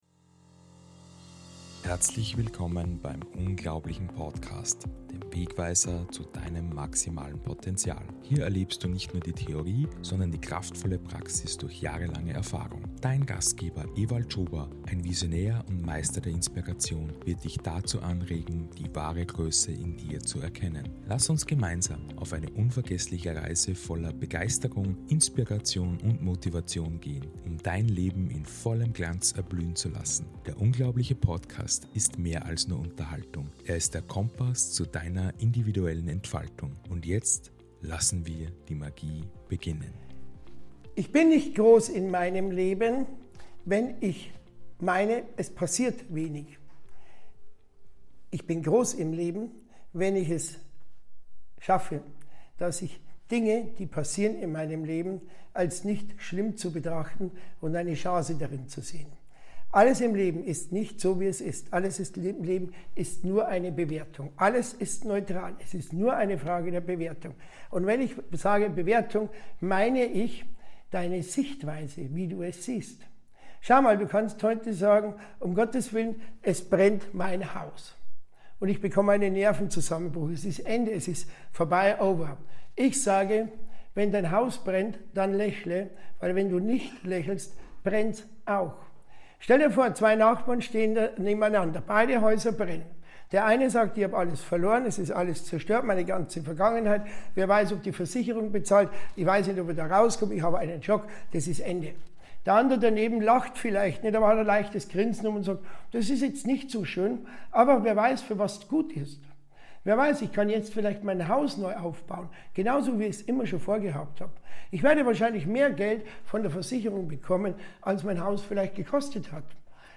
Musik: The Success by Keys of Moon |